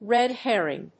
/ˈrɛˈdhɛrɪŋ(米国英語), ˈreˈdherɪŋ(英国英語)/
アクセントréd hérring